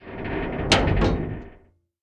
ambienturban_6.ogg